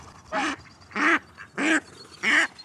chant canard